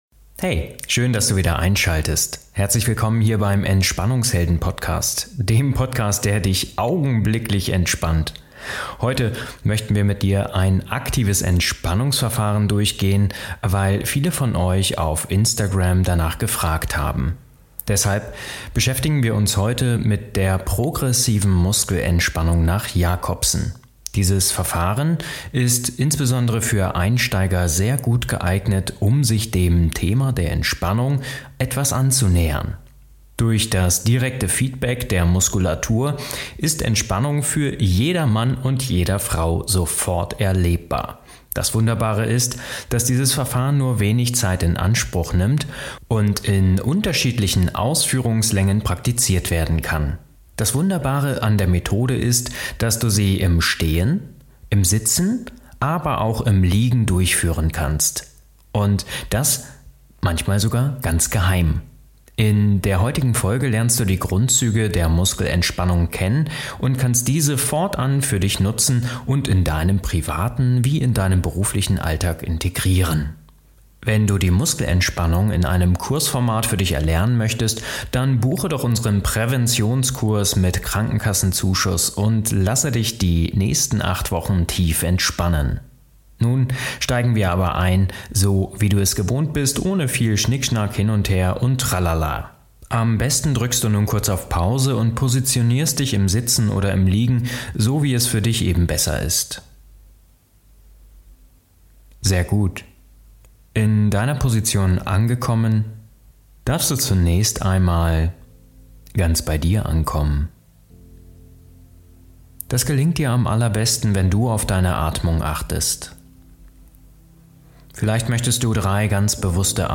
In dieser Podcastfolge erlebst du innerhalb 30 Minuten die Progressive Muskelentspannung mit Musik. Ich leite für dich das Verfahren nach Edmund Jacobson mit den 16 Muskelgruppen an.